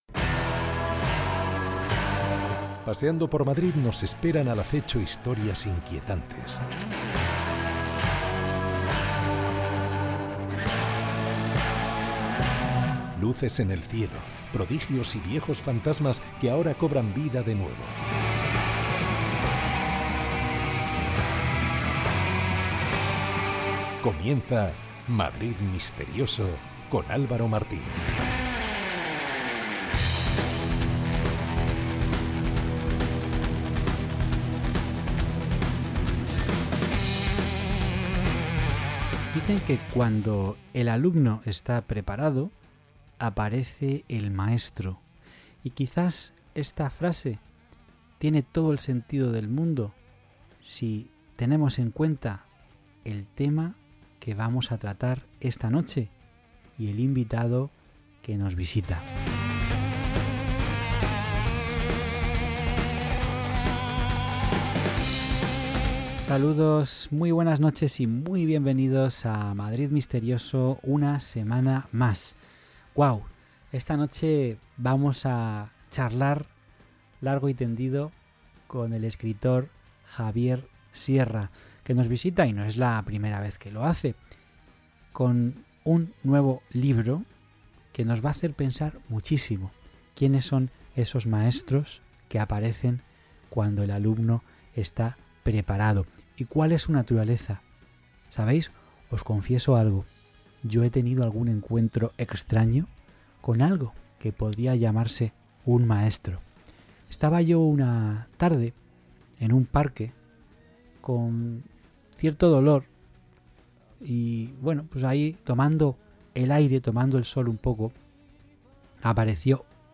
Esta semana nos visita el escritor Javier Sierra para presentar su nueva novela El plan maestro, donde nos invita a conocer a los misteriosos maestros que habitan alrededor de ciertas obras de arte.
Charlamos con el ganador del premio Planeta 2017 sobre su labor de escritura, sobre el enigma que oculta Las Meninas de Velázquez o sobre esos enigmáticos maestros instructores que llevan milenios visitando a la humanidad.